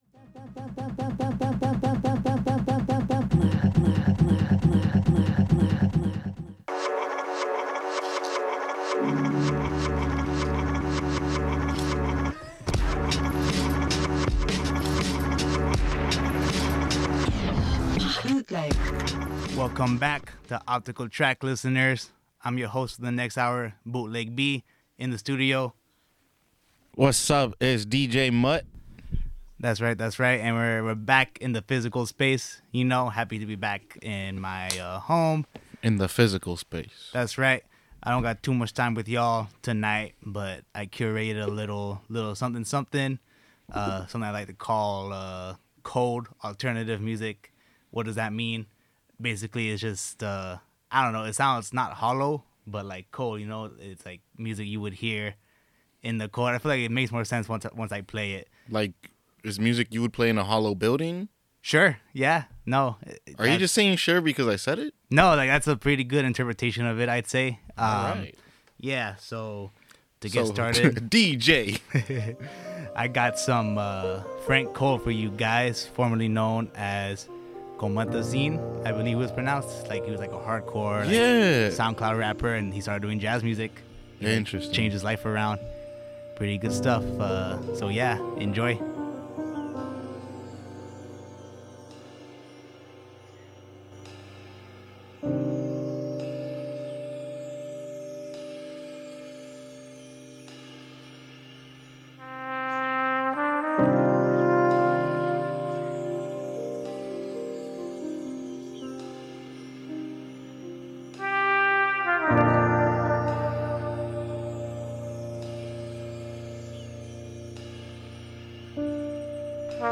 Alternative Hip Hop Rap Soul